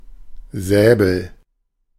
Ääntäminen
Synonyymit coupe-choux zigomar Ääntäminen France (Paris): IPA: [ɛ̃ sabʁ] Tuntematon aksentti: IPA: /sabʁ/ IPA: /sɑbʁ/ Haettu sana löytyi näillä lähdekielillä: ranska Käännös Ääninäyte Substantiivit 1.